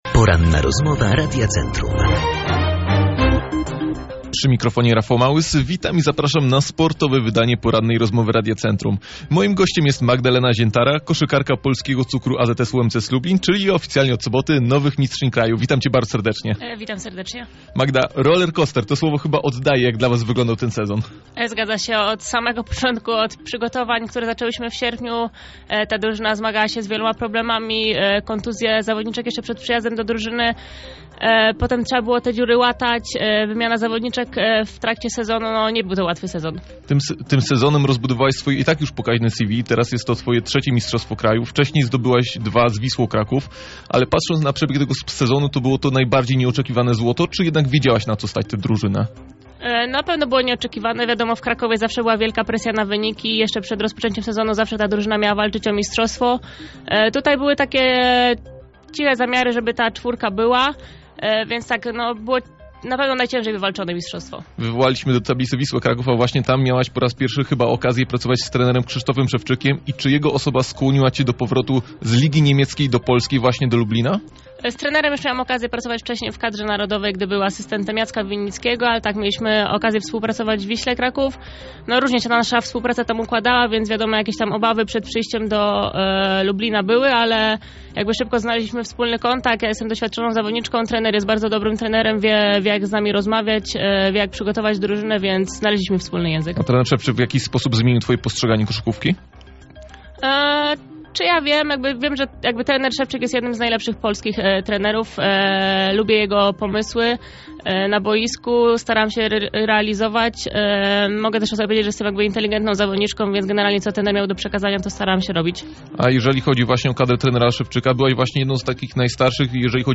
Cała rozmowa dostępna poniżej.